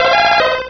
Cri d'Osselait dans Pokémon Rubis et Saphir.